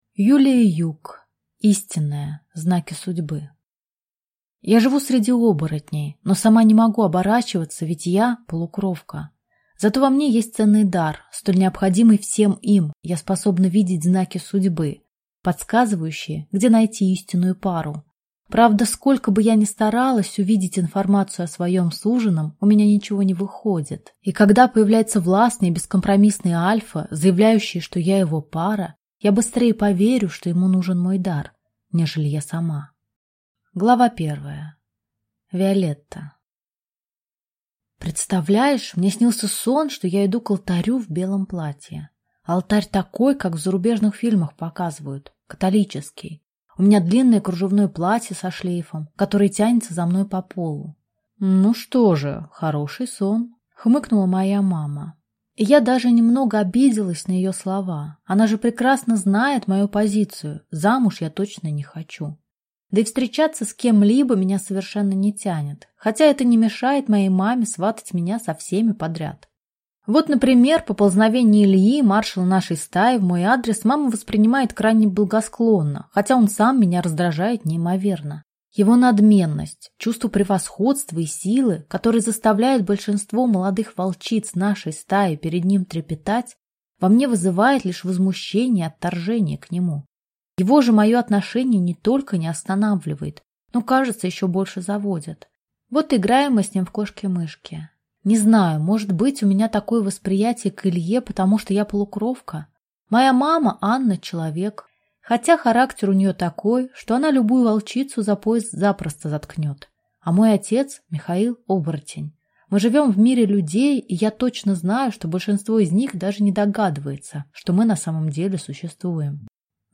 Аудиокнига Истинная: знаки судьбы | Библиотека аудиокниг